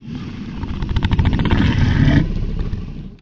rumble1.wav